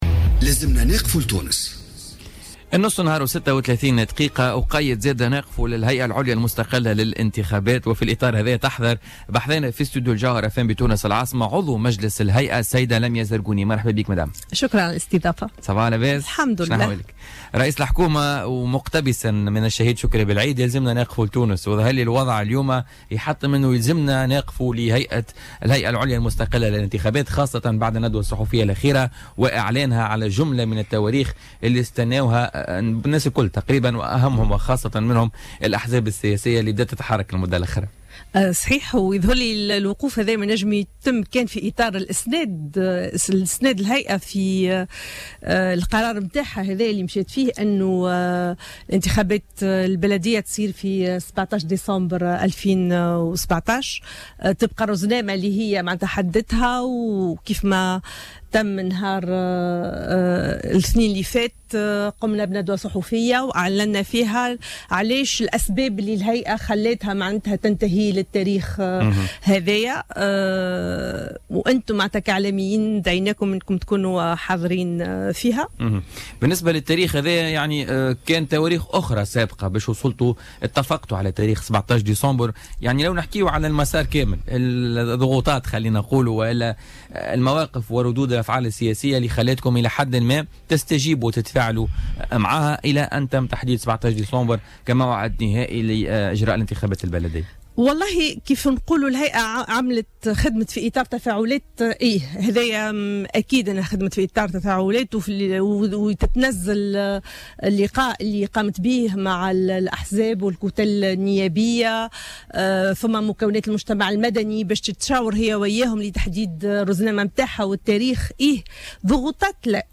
أكدت عضو مجلس الهيئة العليا المستقلة للانتخابات لمياء الزرقوني ضيفة بولتيكا اليوم الأربعاء 5 أفريل 2017 أنه يجب اسناد الهيئة في قرارها الذي اتخذته بإجراء الإنتخابات في موعد 17 ديسمبر وفق رزنامة حددتها.